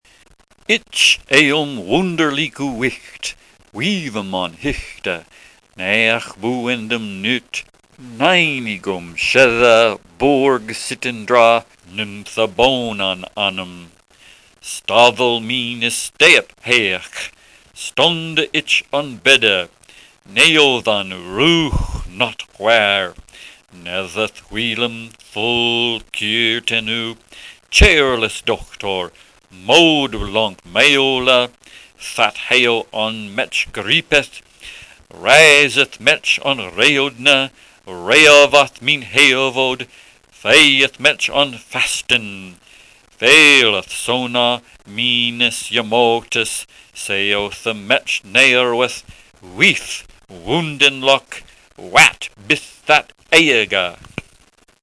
52. Enigme d’Exeter ( l’oignon/bite) e,n vieil-anglais (reconstitution par des spécialistes.
Quand on entend ces sons, on se demande comment cela a pu évoluer vers l’angalis moderne.